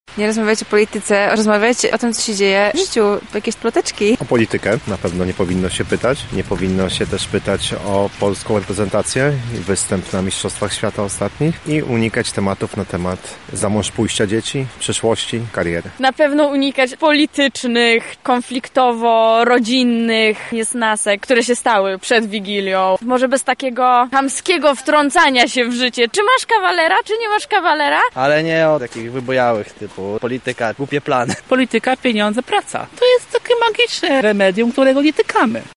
Są tematy, których podczas świątecznej biesiady powinniśmy unikać. Swoje zdanie na ten temat, przedstawili nam mieszkańcy Lublina: